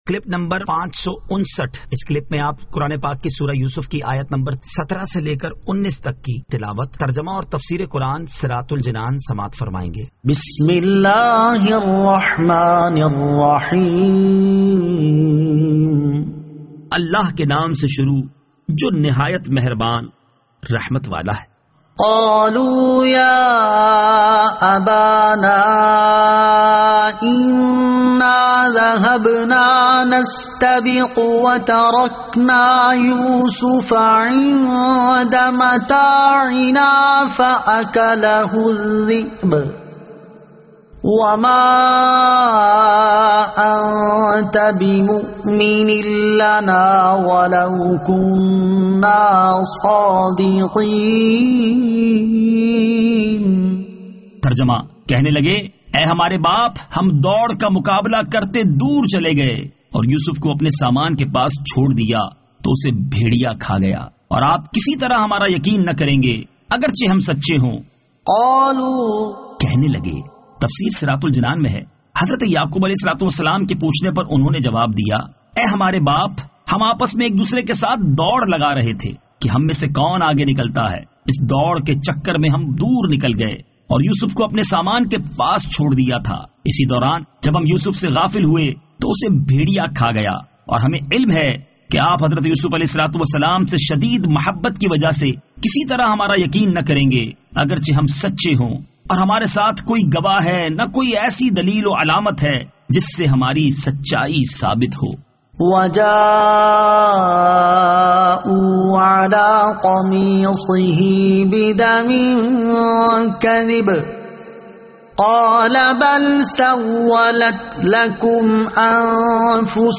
Surah Yusuf Ayat 17 To 19 Tilawat , Tarjama , Tafseer